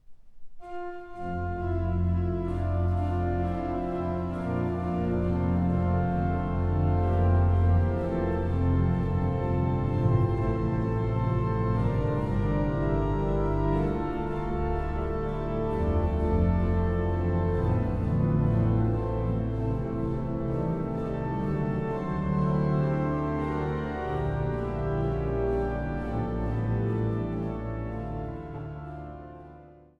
Der Mitteldeutsche Rundfunk zeichnete das Konzert auf.